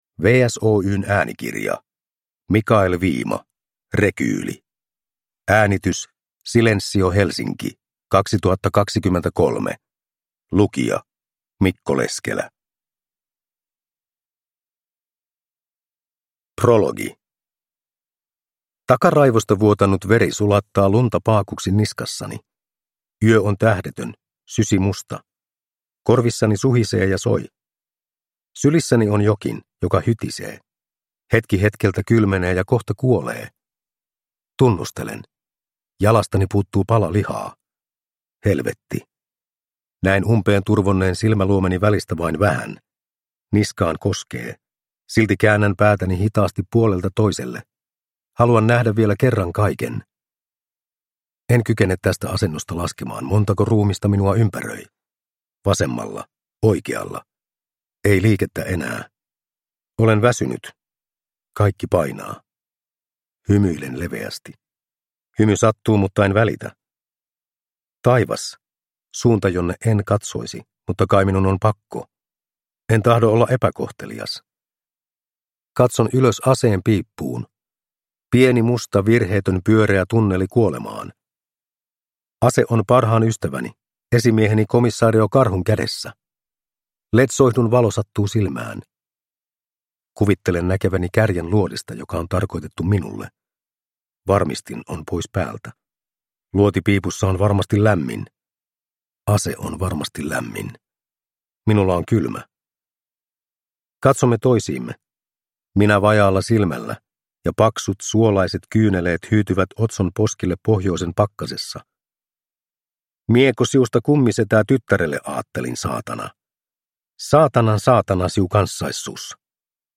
Rekyyli – Ljudbok – Laddas ner